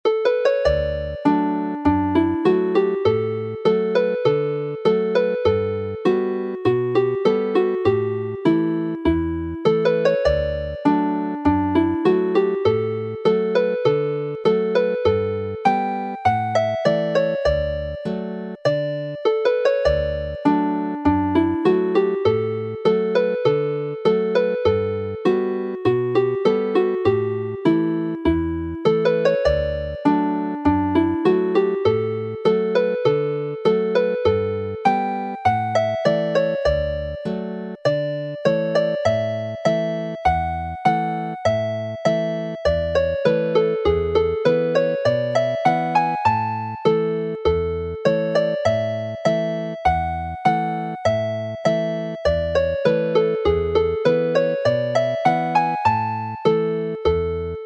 Play the reel / polka